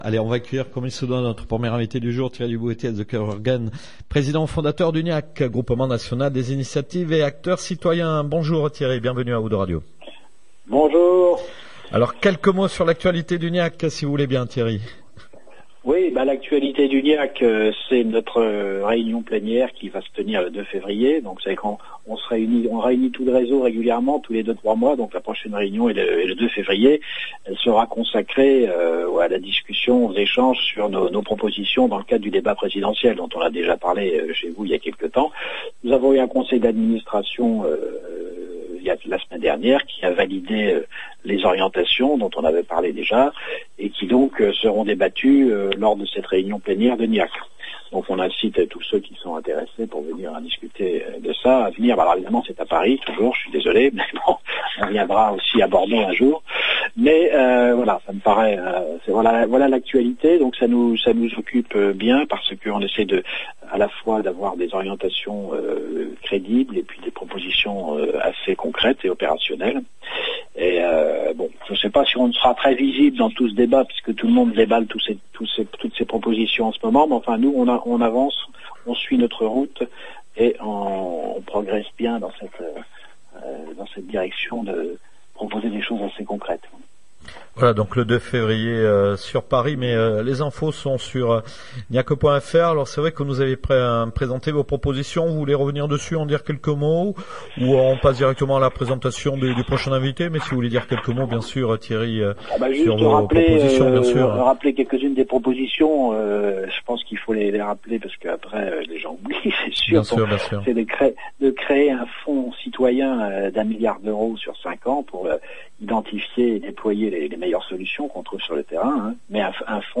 Interview ici ITW-O2RadioGNIAC-19-01-17.mp3